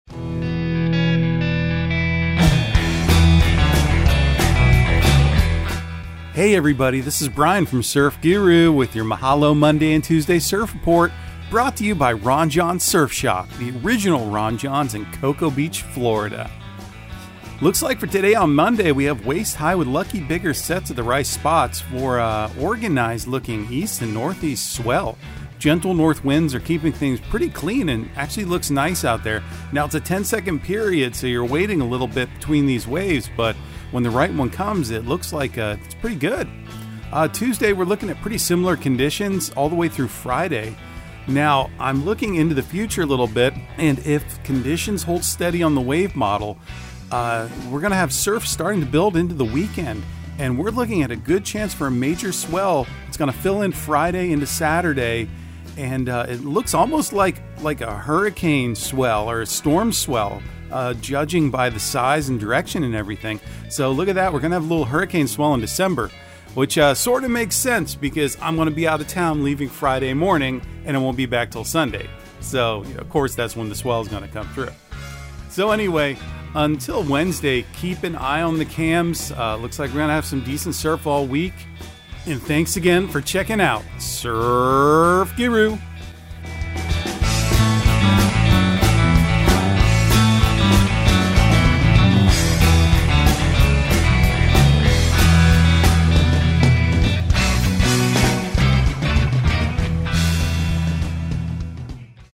Surf Guru Surf Report and Forecast 12/05/2022 Audio surf report and surf forecast on December 05 for Central Florida and the Southeast.